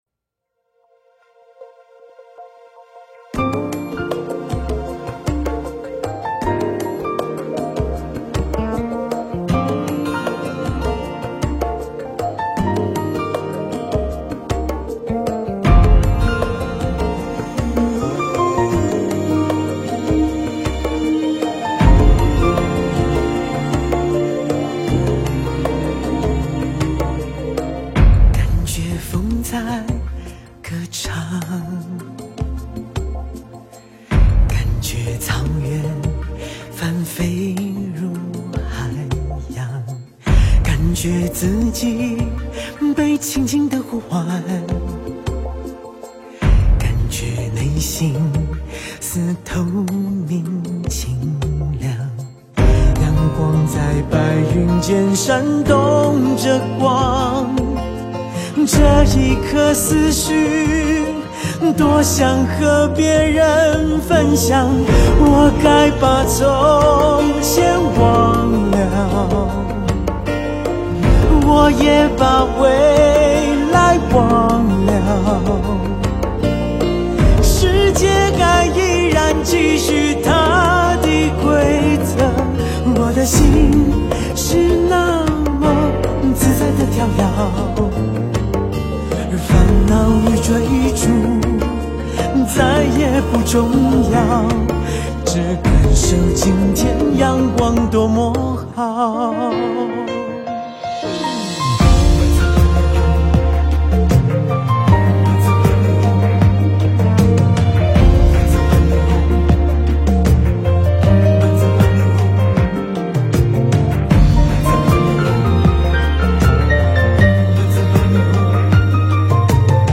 佛音 诵经 佛教音乐 返回列表 上一篇： 忿怒莲师法乐供赞之一(法乐